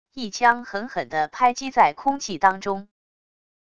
一枪狠狠的拍击在空气当中wav音频